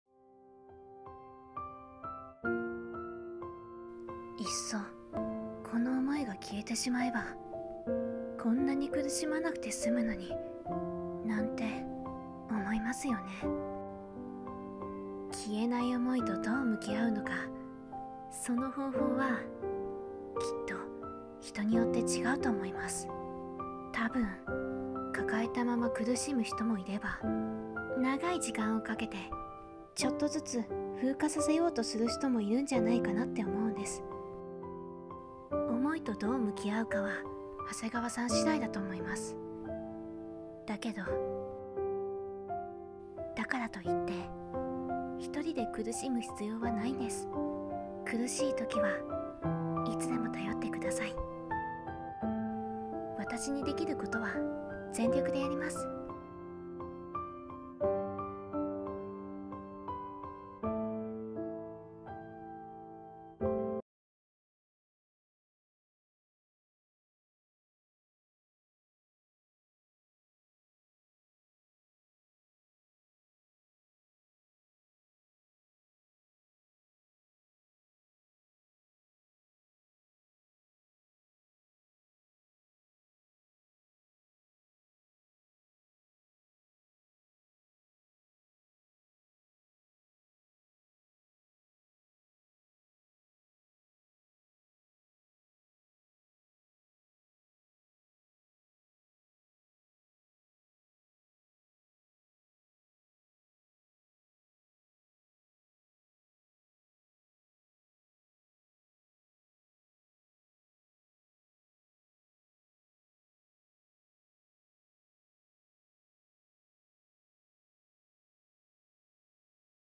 桐谷遥声真似 いふイベ